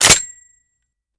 lowammo_dry_handgun.wav